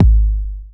Kick 8.wav